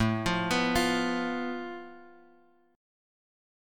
Asus2#5 chord {5 x 3 4 6 x} chord